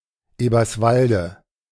Eberswalde (German pronunciation: [ˌʔeːbɐsˈvaldə]